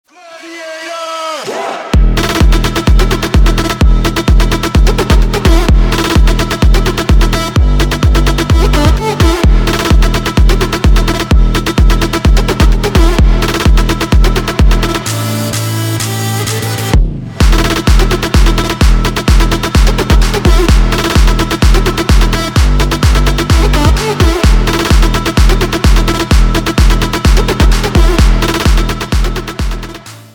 громкие
EDM
Стиль: electro house